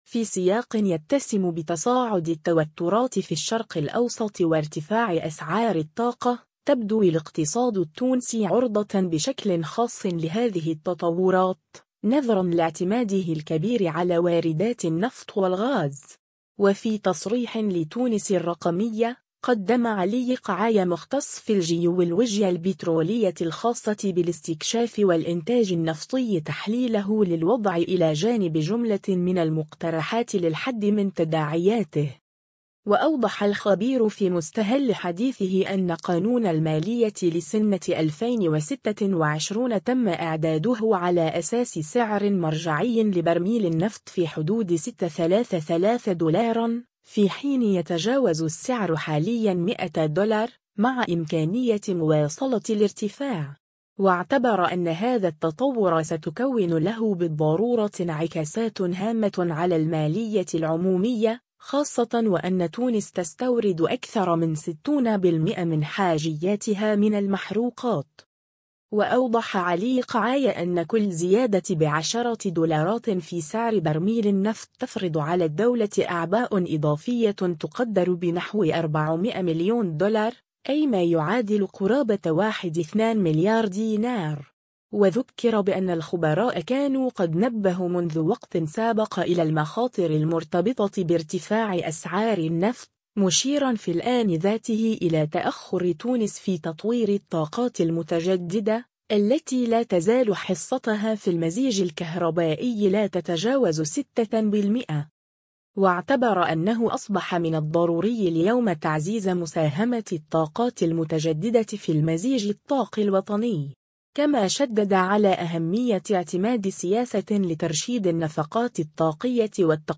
ماهو تأثير ارتفاع أسعار النفط على تونس و ماهي الحلول العاجلة التي يجب اتخاذها ؟ (تصريح)